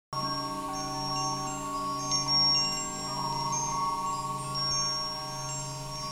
Many people find that listening to the frequencies is soothing and relaxing and creates a positive change.
Sample-of-Biofeedback-Energetic-Frequencies.mp3